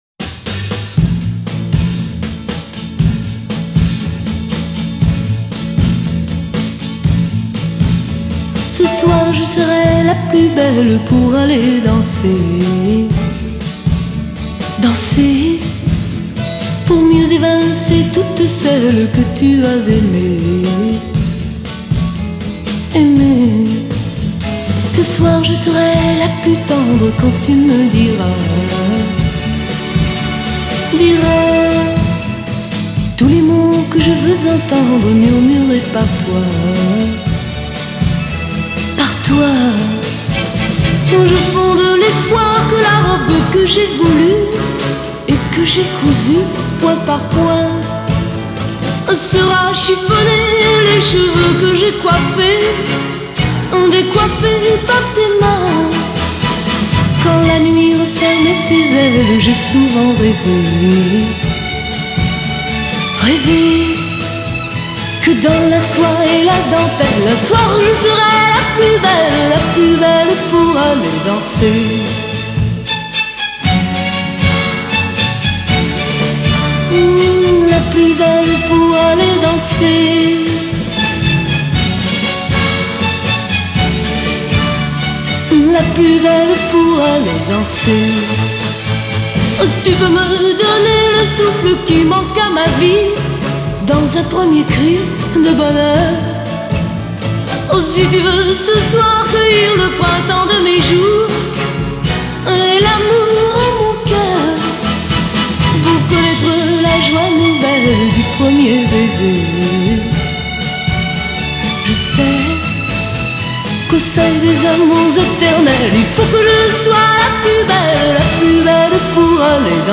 Genre: Pop, French, Chanson